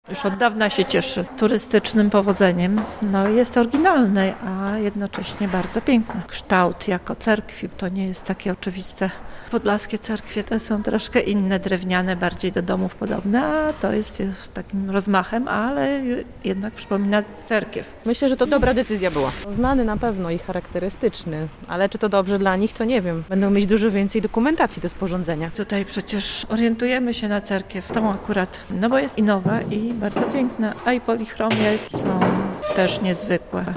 Mieszkańcy Hajnówki o soborze Świętej Trójcy